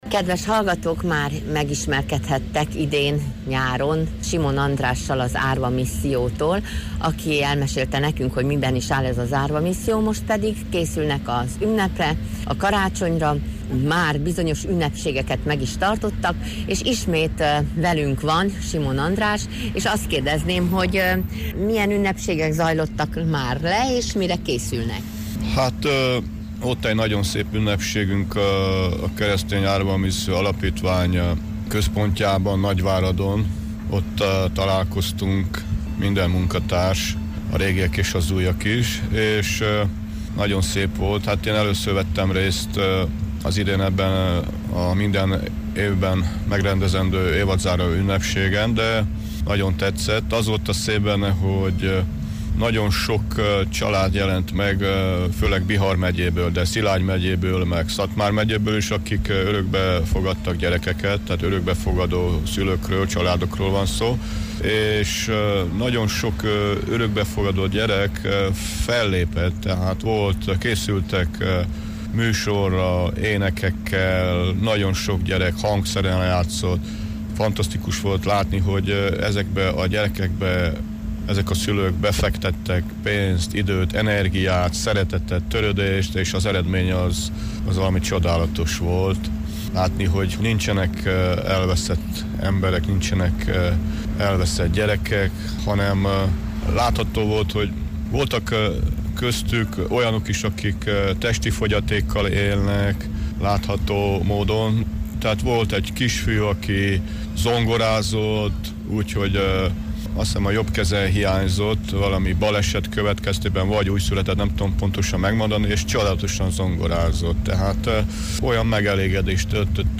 a vele készült beszélgetés itt hallgatható újra: